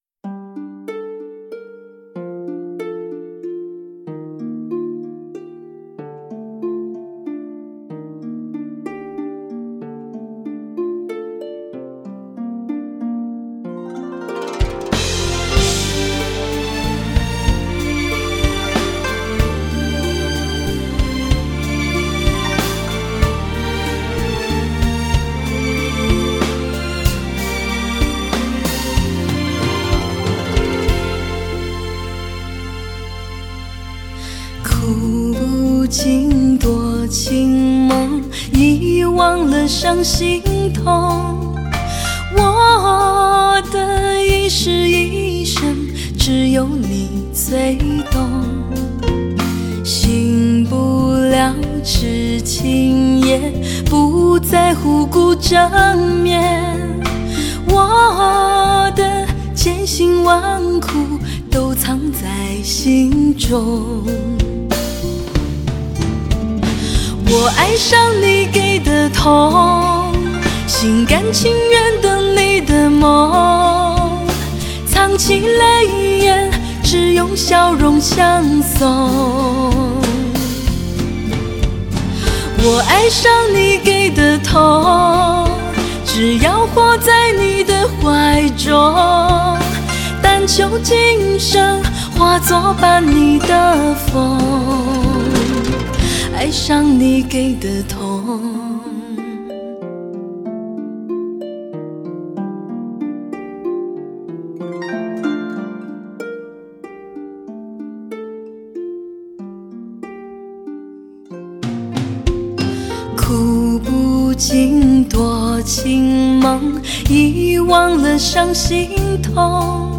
未见其人 先闻其声 集合十年HIFI女声精华
甜美 空灵 透彻 幽怨 感性 听她们的声声倾诉